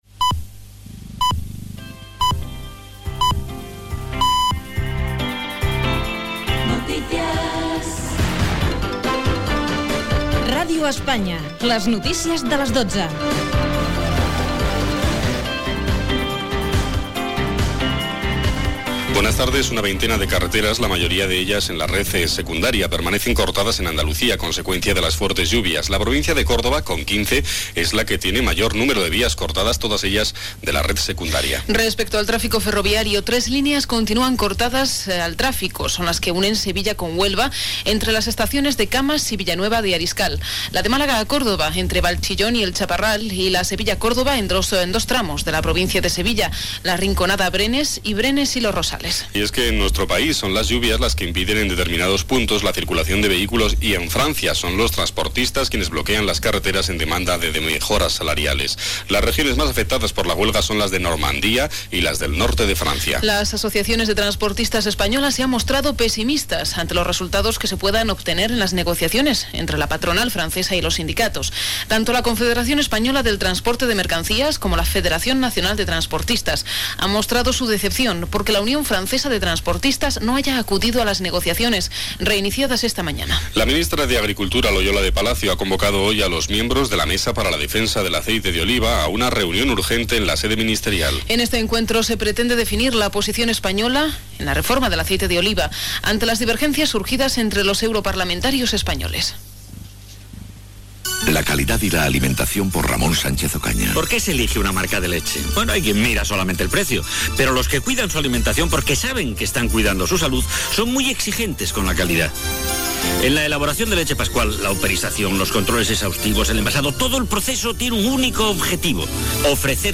Careta del programa. Talls a les carreteres i trens a Andalusia degut al mal temps, talls a les carreteres franceses per les protestes dels transportistes , etc. Publicitat amb Ramón Sánchez Ocaña.
Informatiu